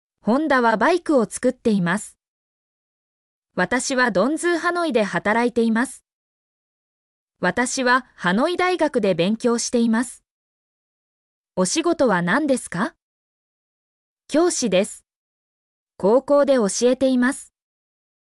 mp3-output-ttsfreedotcom-39_ijkwAp5V.mp3